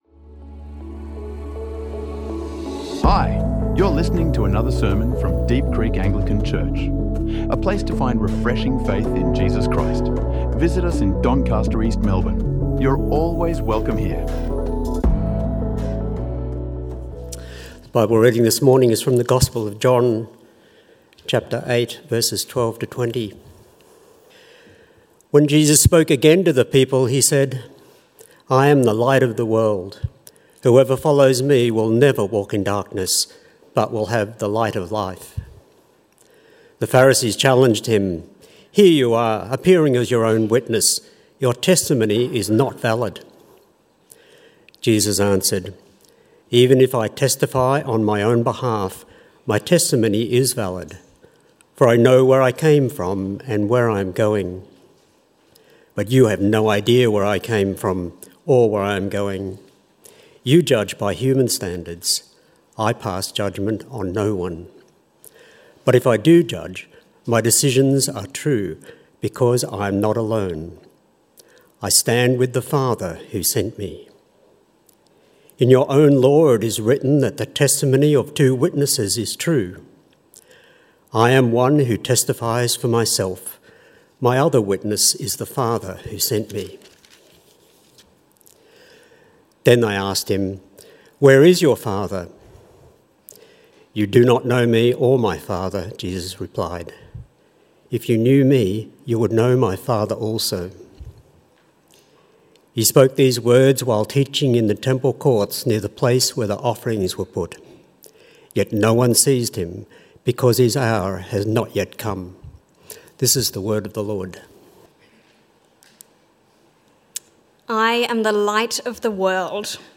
A sermon on truth, forgiveness, and life found only in Him.